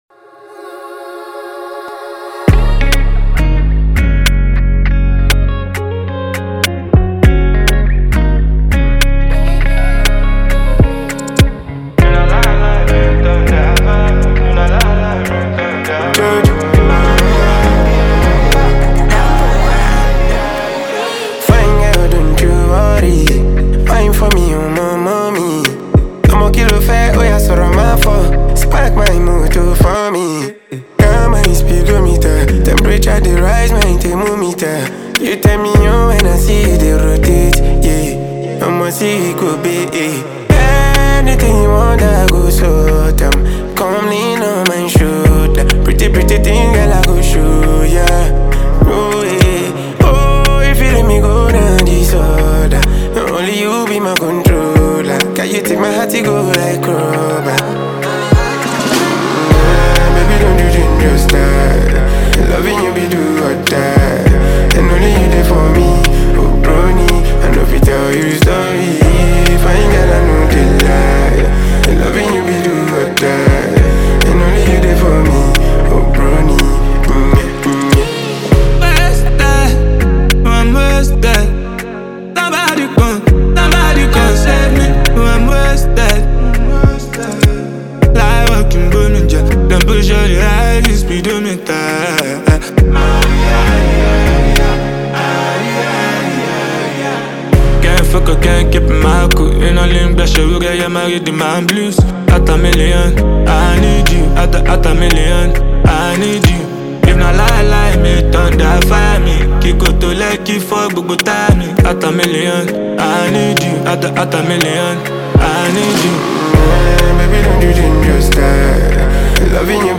dope jam